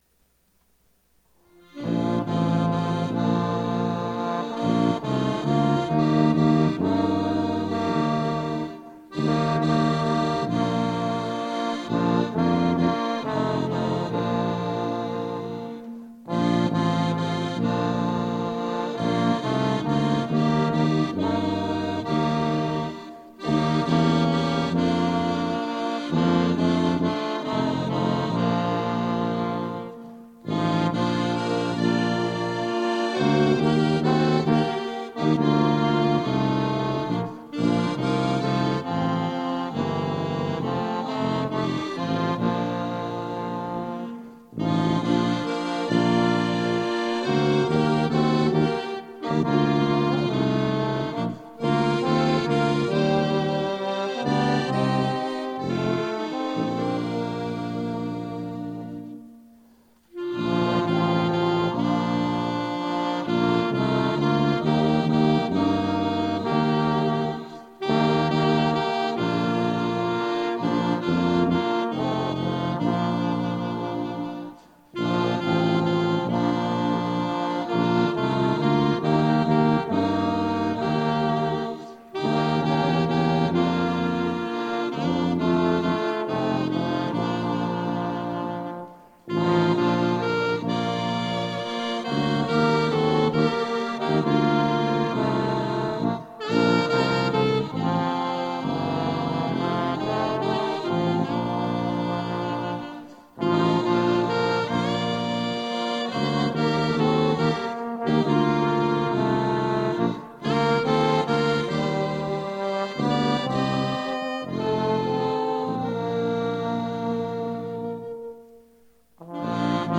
acordeon
sax-alto
trombone
tuba